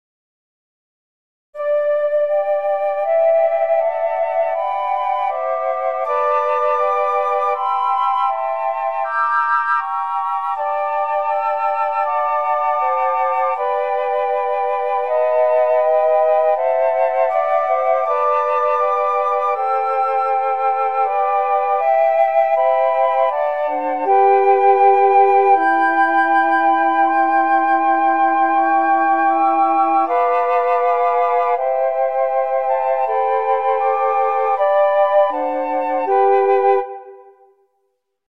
【編成】フルート四重奏（4Flute / 3 Flute and 1 Alto Flute）*
グっとテンポを落として、かなりモダンにリハーモナイズ（新しい和声付け）してあります。２コーラスあります。
4フルートまたは、3フルート＆1アルトフルートの編成になります。